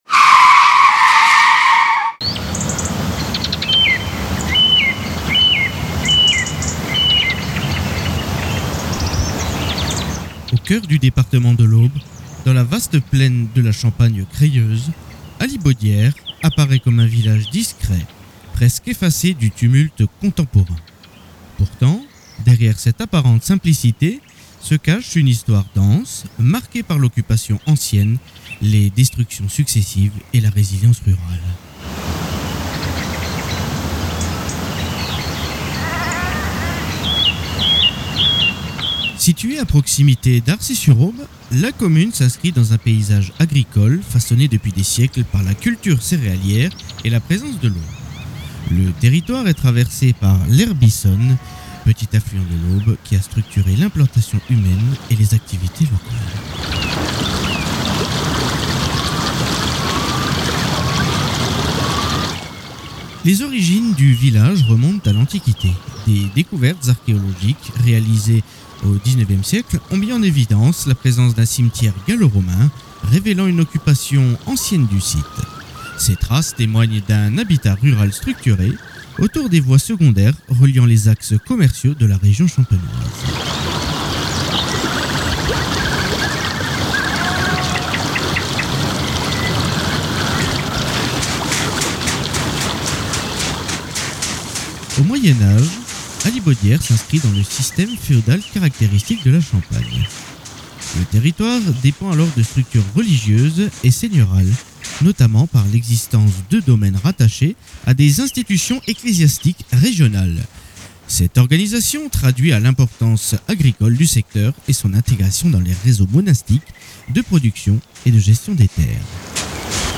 Dans ce reportage, partez à la découverte de ce village où le temps semble ralentir, et où chaque coin de rue révèle un petit morceau d’histoire.